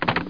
Hangup.mp3